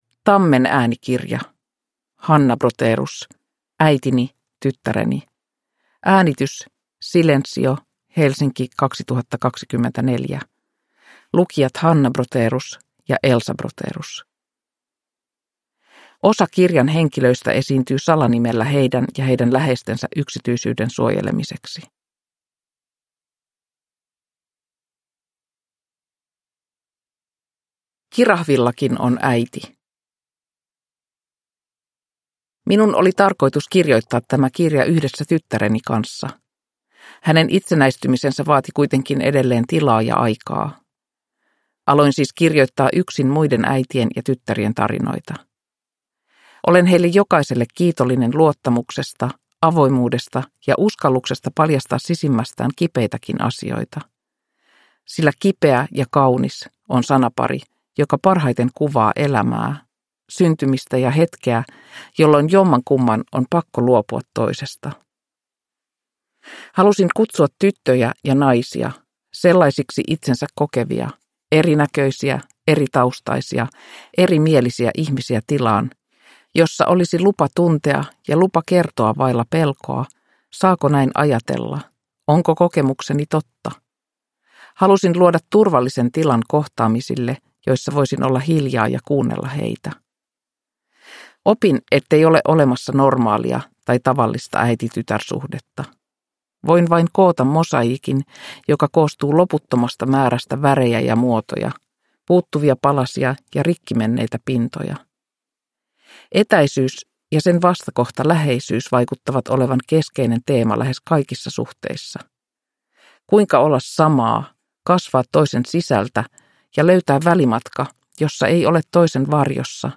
Äitini, tyttäreni – Ljudbok